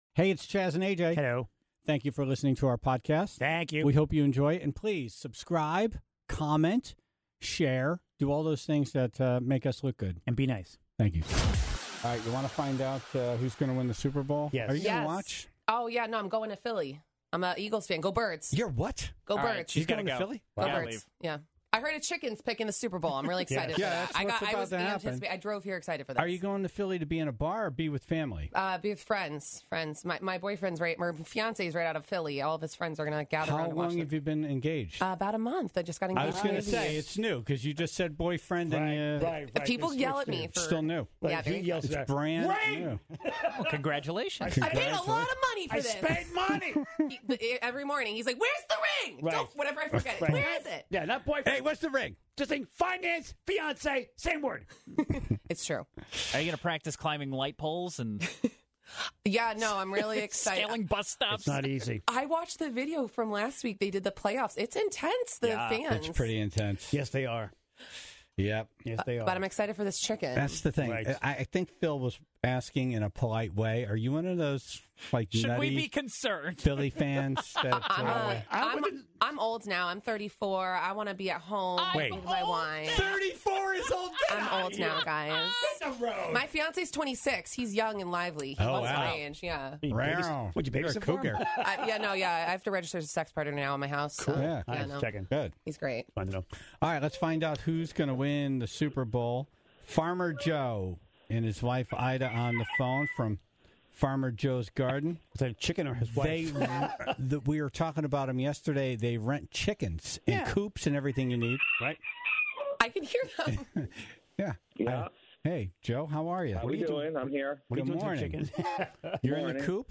(11:59) Dumb Ass News - The best flubbles of the week!
Everyone takes a hit in the montage for this week.